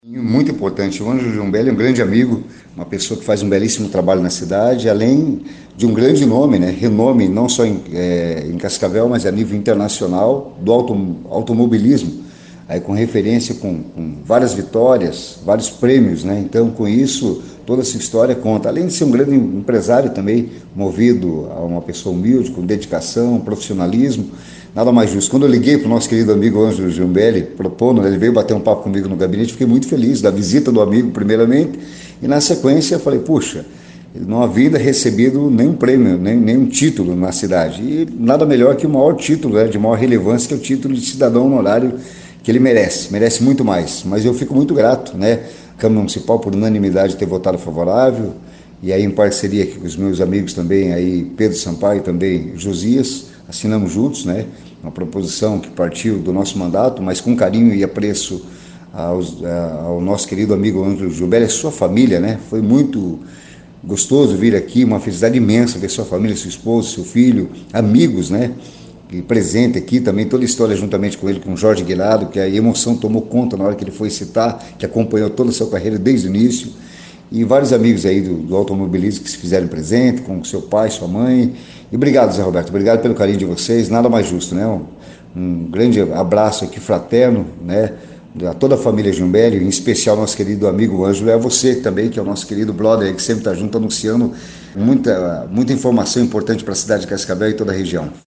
Em entrevista à CBN